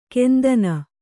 ♪ kendana